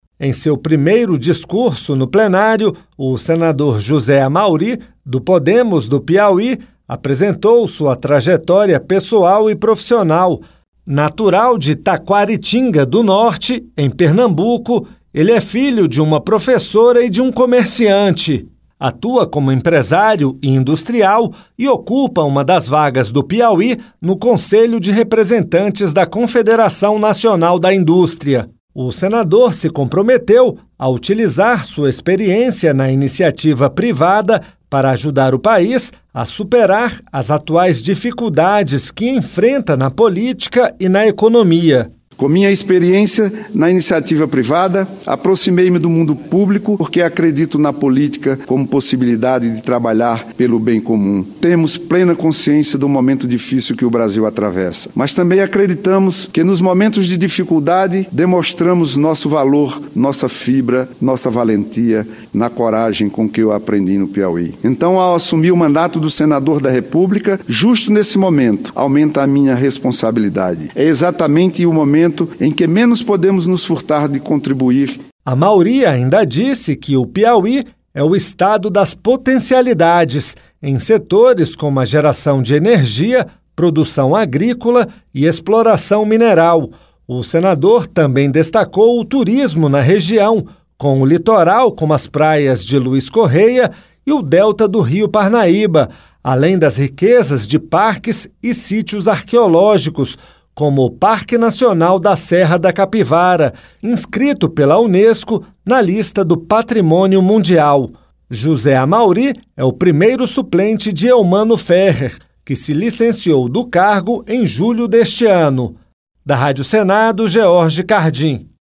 Em seu primeiro discurso no plenário, o senador José Amauri (Pode-PI) apresentou sua trajetória pessoal e profissional.